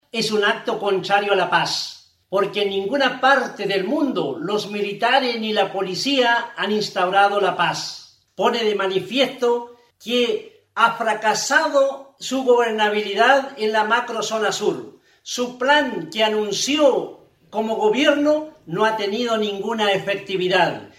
ds-alcalde-3.mp3